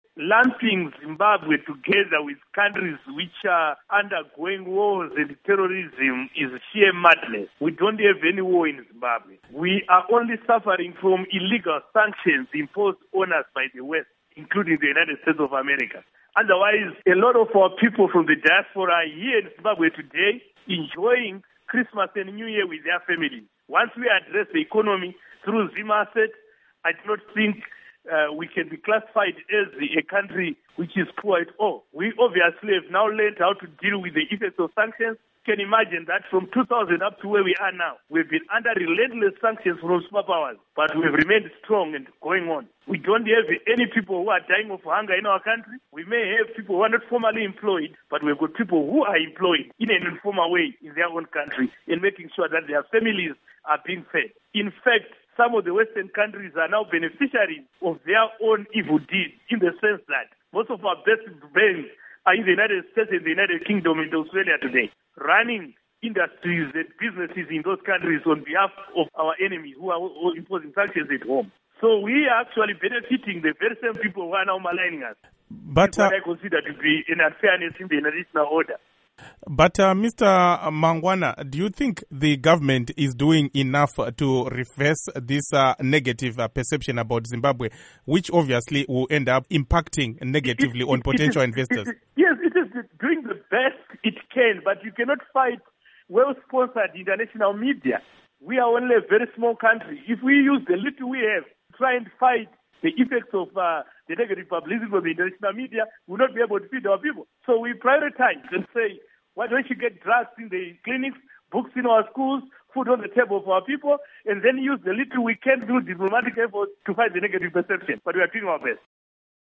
Interview with Paul Mangwana on Fragile States Index